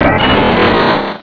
-Replaced the Gen. 1 to 3 cries with BW2 rips.